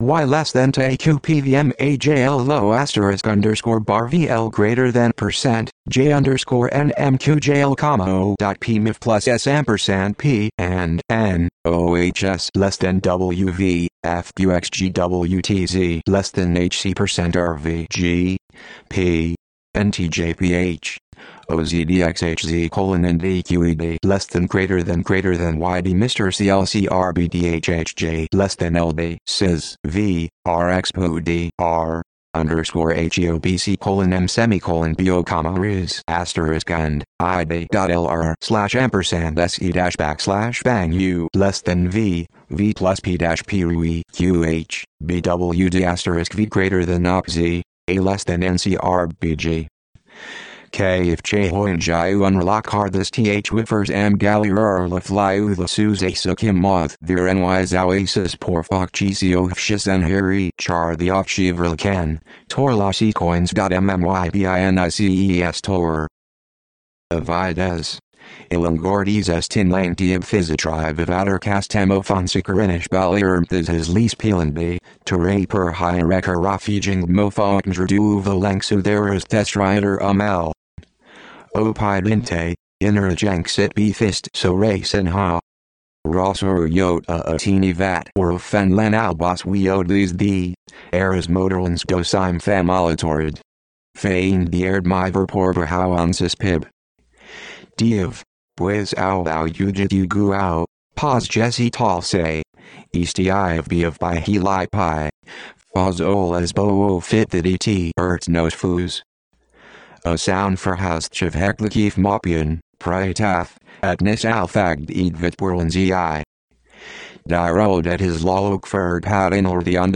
• *An audiobook version is also available –
audiobook_rnn_fw.mp3